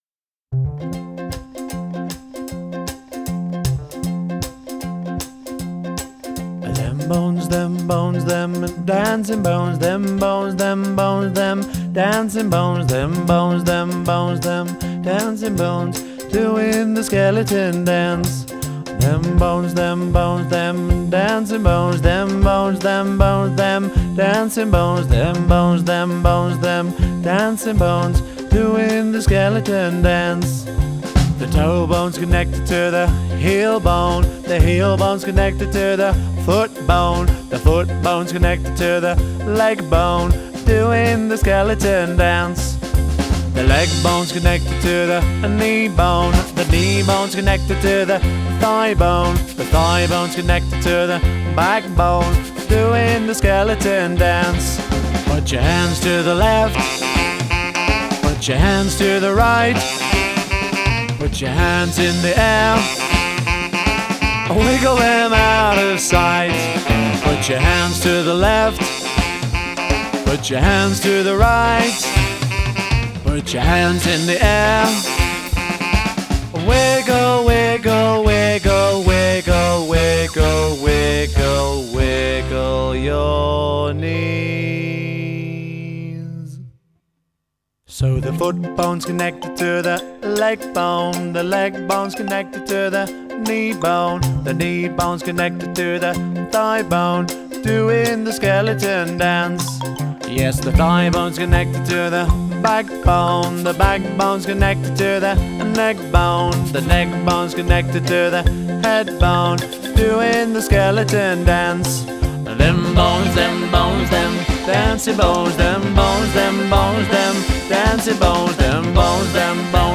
SpookyFun & Punk